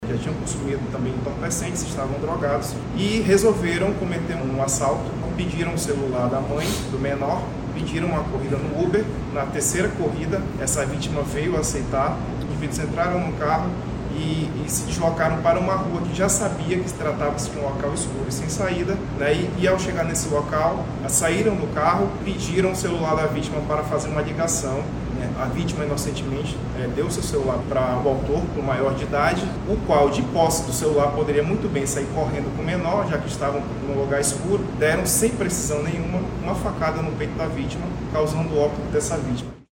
Em coletiva de imprensa nesta quarta-feira